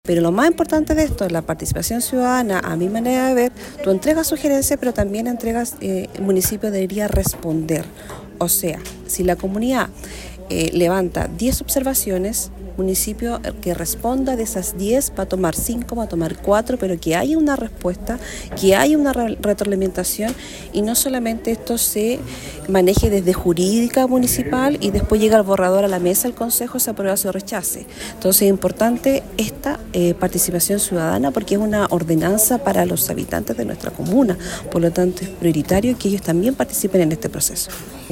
La Concejala de Osorno, Cecilia Canales, realizó este martes en la sesión ordinaria del Concejo Municipal, una solicitud al Alcalde Emeterio Carrillo para que la actual ordenanza de humedales se socialice con la comunidad antes de pasar por a votación.